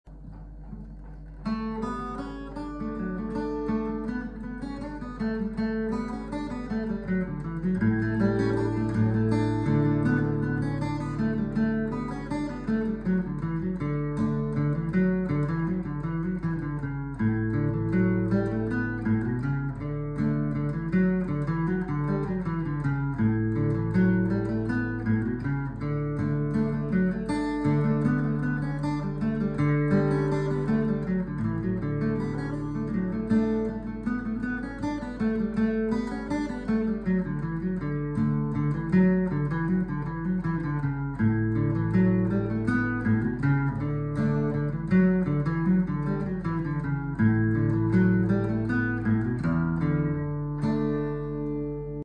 Key: D
Form: Reel
Source: Trad.